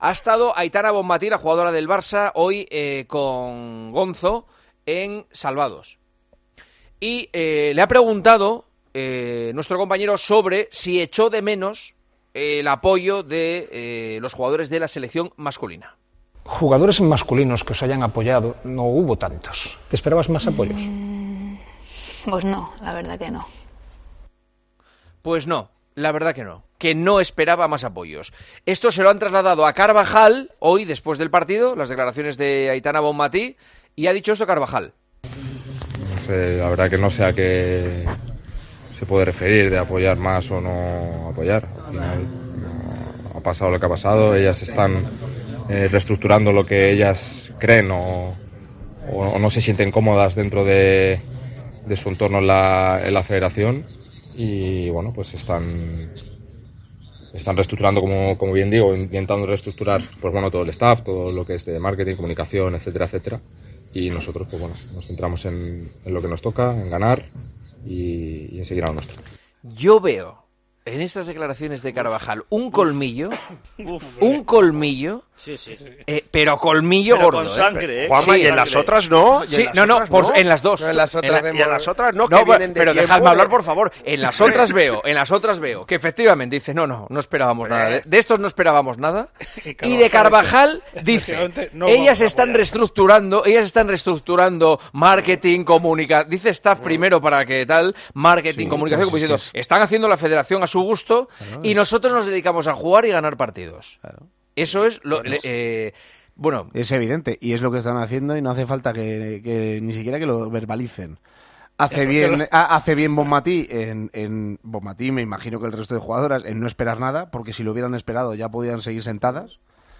Vuelve a escuchar 'El Tertulión de los Domingos' celebrando la clasificación de España para la Eurocopa de Alemania, con Juanma Castaño y los comentaristas de Tiempo de Juego.